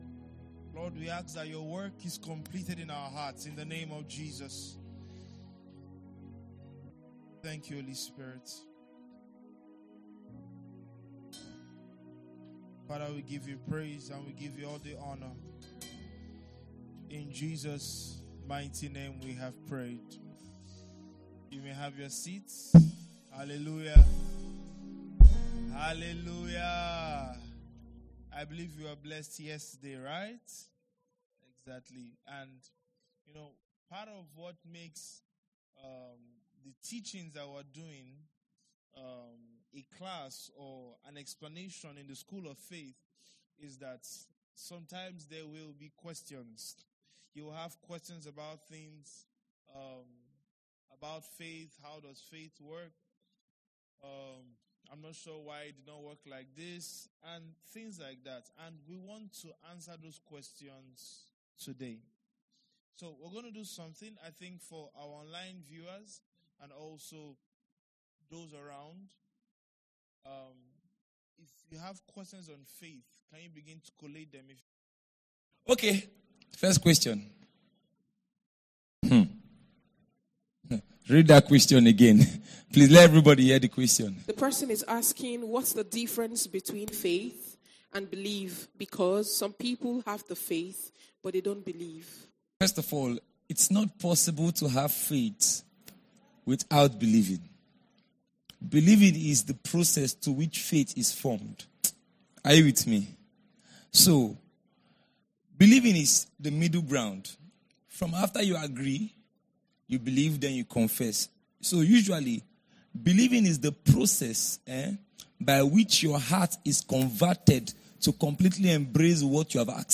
The Word of Faith (Questions and Answers Session).mp3